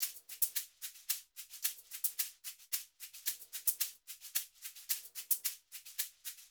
WVD SHAKER 1.wav